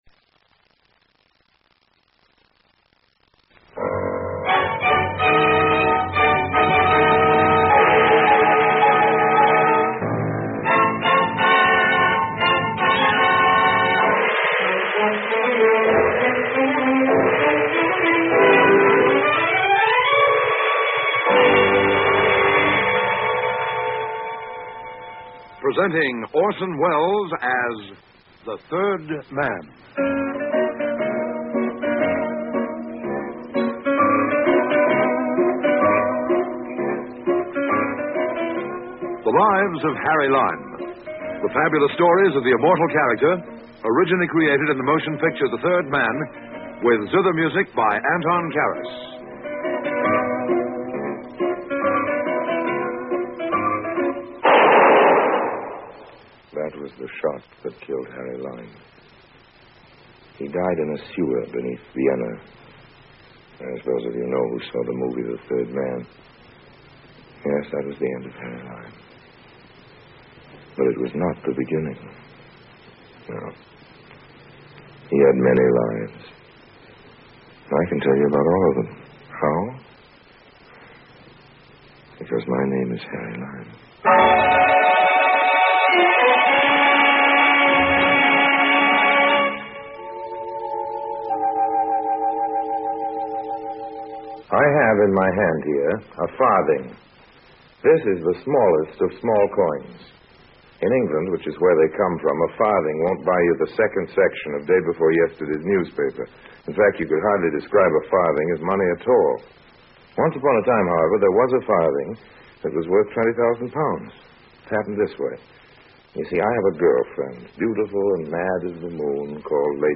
The Adventures of Harry Lime is an old-time radio programme produced in the United Kingdom during the 1951 to 1952 season. Orson Welles reprises his role of Harry Lime from the celebrated 1949 film The Third Man. The radio series is a prequel to the film, and depicts the many misadventures of con-artist Lime in a somewhat lighter tone than that of the film.